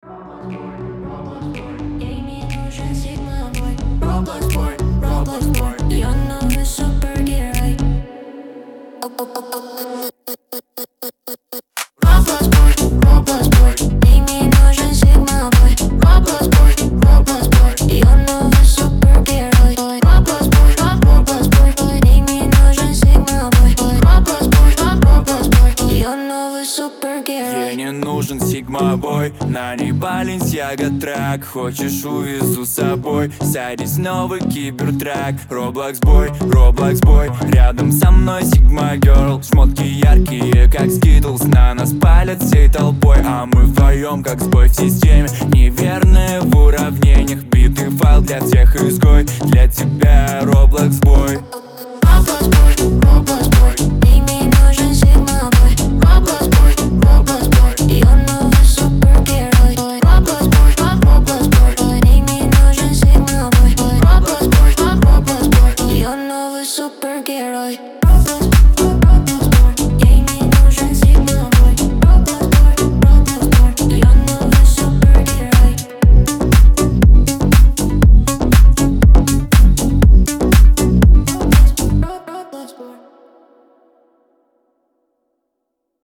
Энергичный и дерзкий гимн